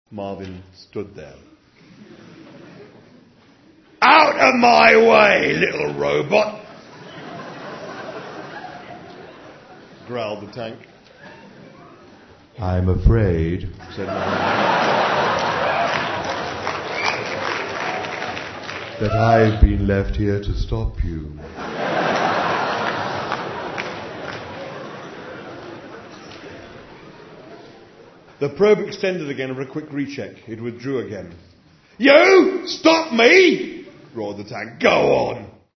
Lesung an der Univerität Göttingen
Ungefähr 900 Leute füllten den Hörsaal und Douglas machte seine Sache wirklich gut. Er sprang auf der Bühne herum, er schrie und warf den Kopf in den Nacken während er die Augen schloss (man kann gar nicht alle Gesten beschreiben) - wirf einfach mal einen Blick auf einige der Fotos, die ich damals gemacht habe.
Mitschnitt der Lesung